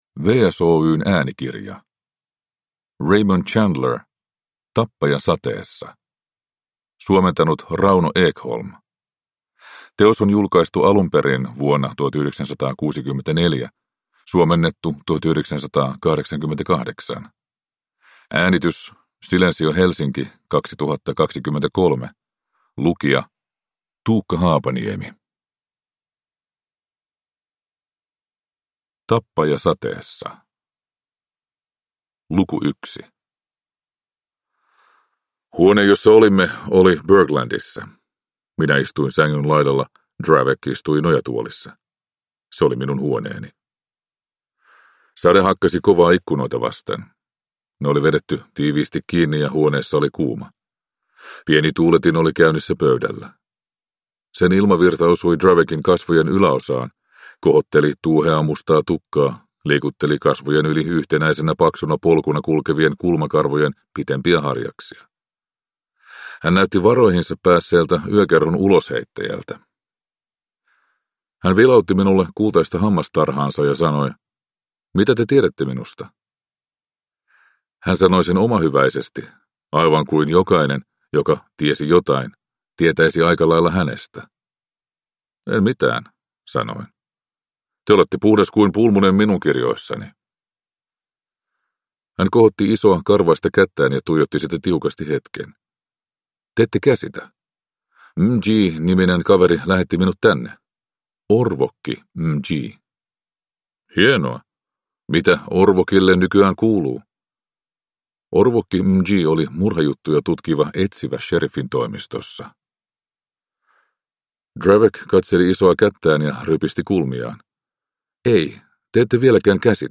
Tappaja sateessa – Ljudbok